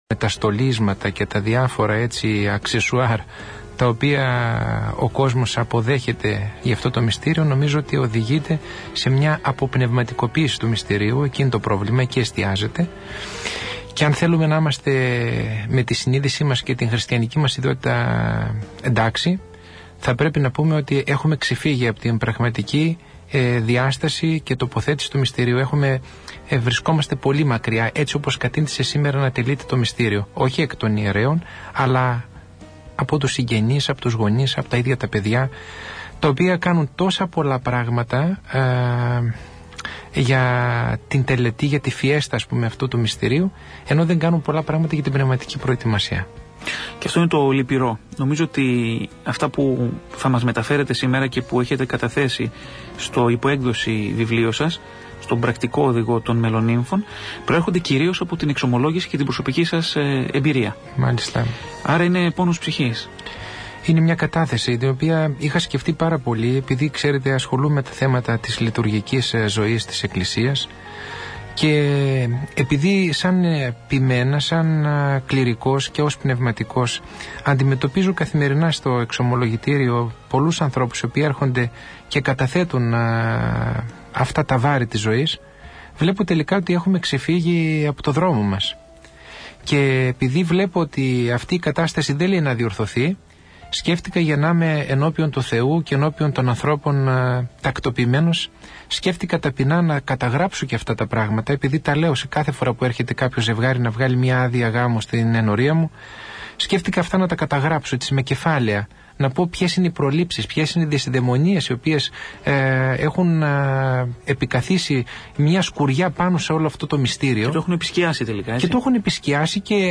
Συζήτηση
από την εκπομπή «Διάλογοι» στον ραδιοφωνικό σταθμό Πειραϊκή Εκκλησία 91,2 FM